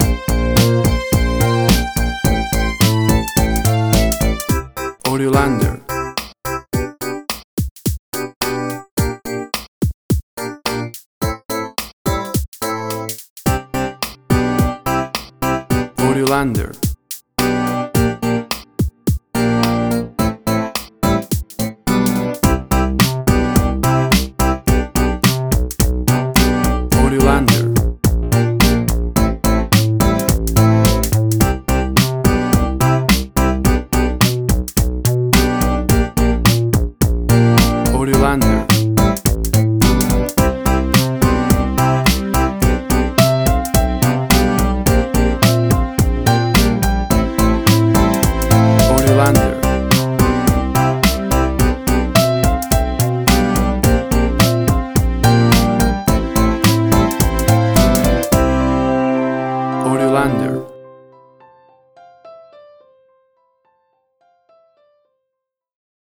WAV Sample Rate: 16-Bit stereo, 44.1 kHz
Tempo (BPM): 107